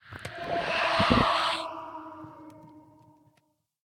ambient_ominous3.ogg